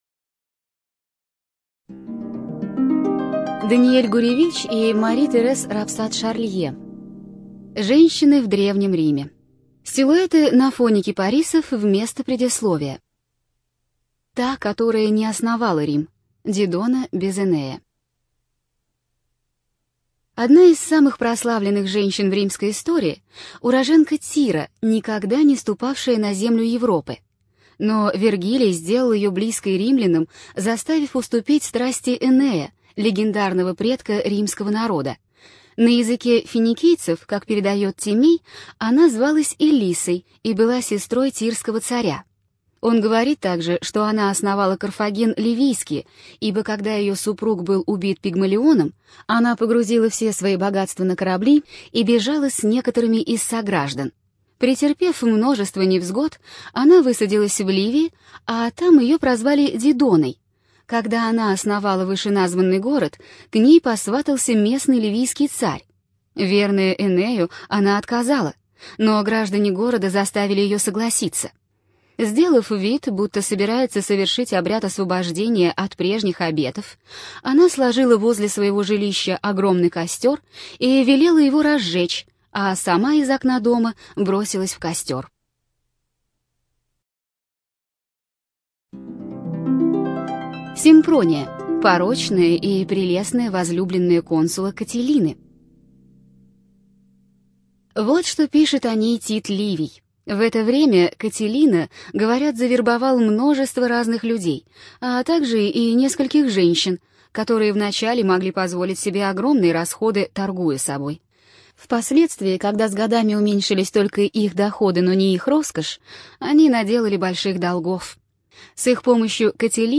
ЖанрБиографии и мемуары